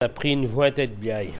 Elle provient de Saint-Jean-de-Monts.
Locution ( parler, expression, langue,... )